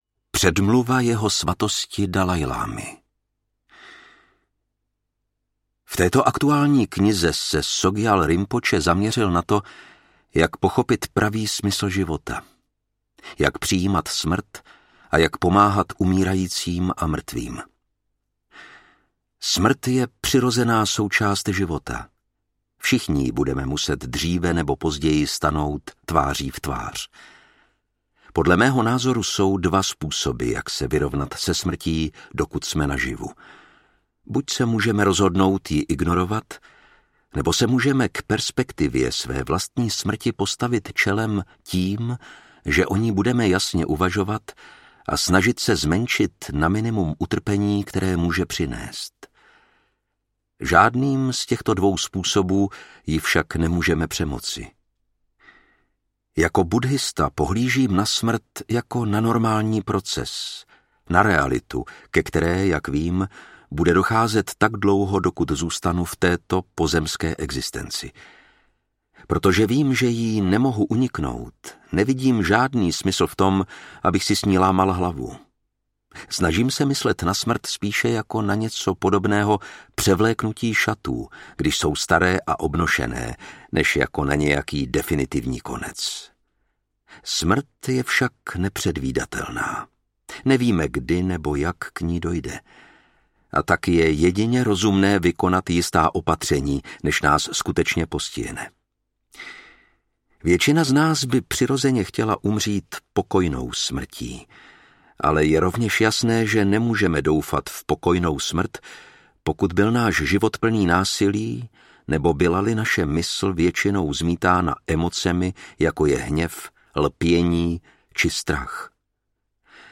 Audio knihaTibetská kniha o životě a smrti
Čte Lukáš Hlavica.
Vyrobilo studio Soundguru.
• InterpretLukáš Hlavica